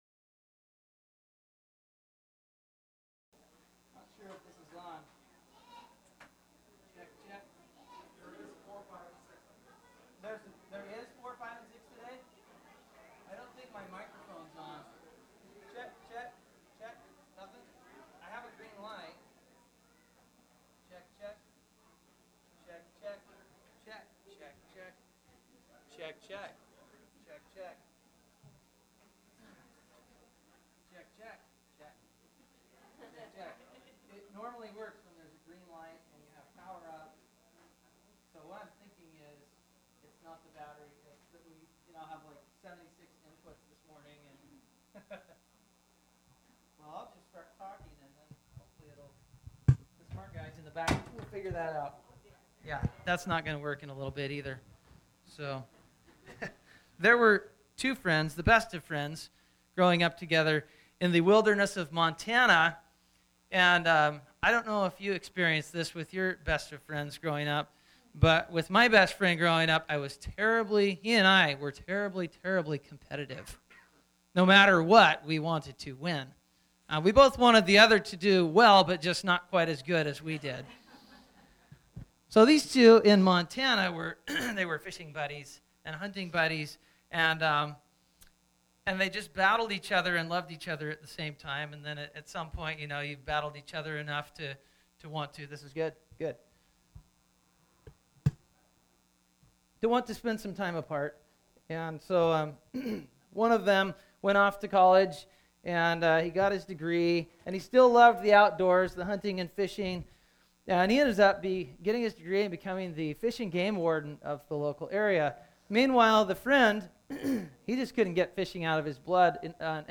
2014 Easter sermon.mp3